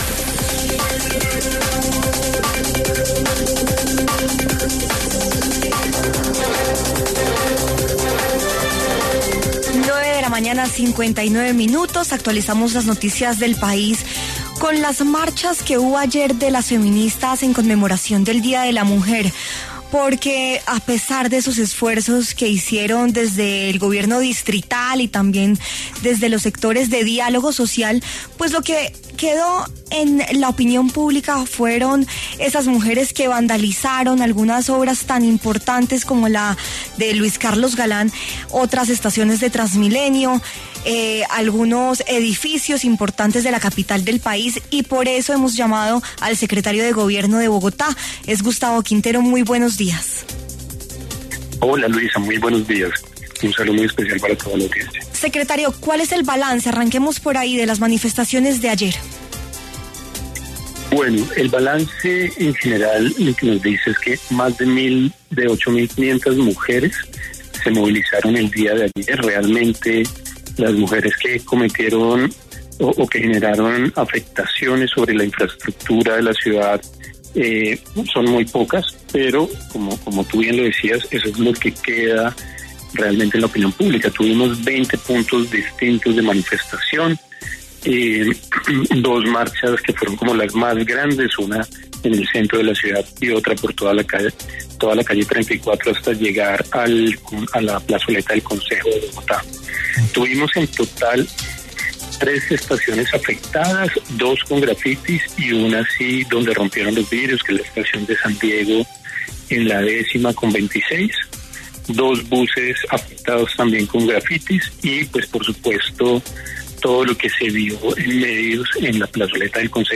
Por esta razón, Gustavo Quintero, secretario de Gobierno de Bogotá, habló en W Fin De Semana y dio detalles de lo sucedido durante estas manifestaciones en el distrito.